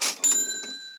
sfx_money.ogg